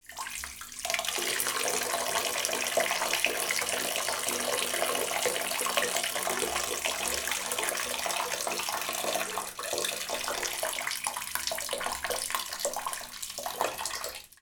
bathroom-sink-23
bath bathroom bubble burp click drain dribble dripping sound effect free sound royalty free Sound Effects